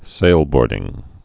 (sālbôrdĭng)